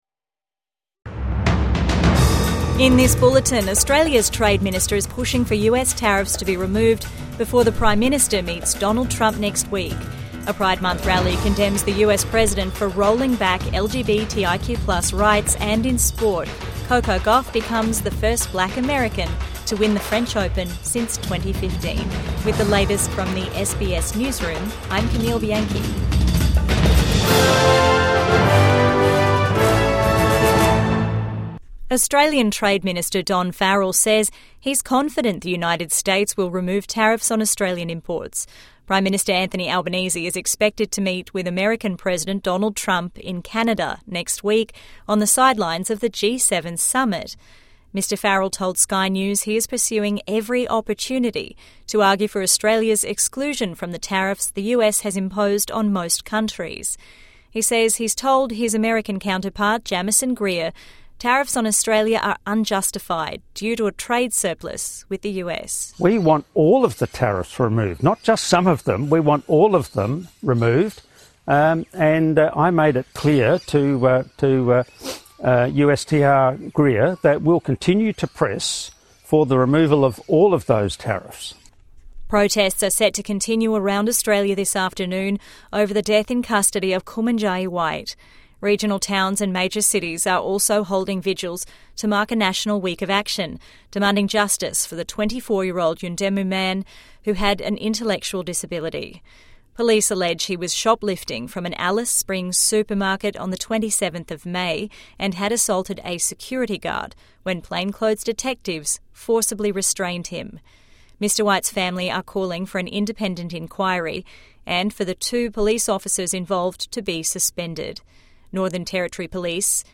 Trade Minister optimistic US tariffs will be removed | Midday News Bulletin 8 June 2025